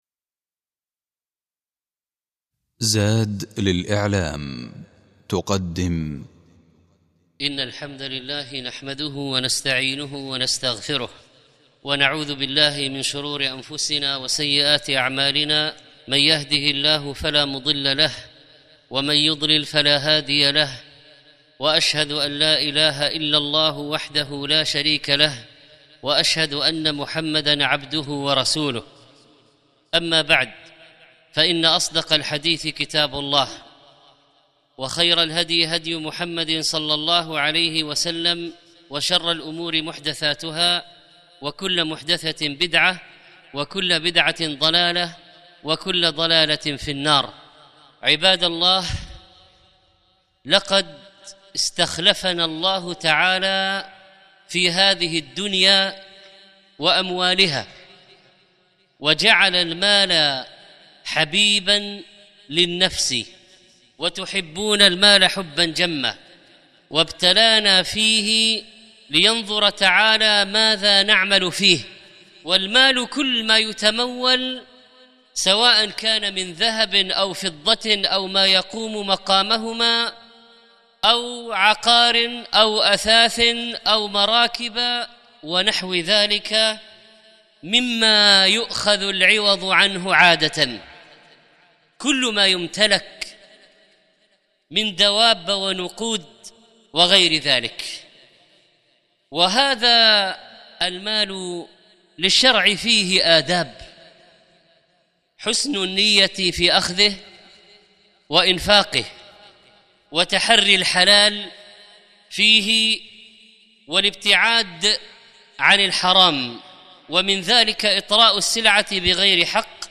الخطبة الأولى آداب المال آداب العقار حرمة الاحتكار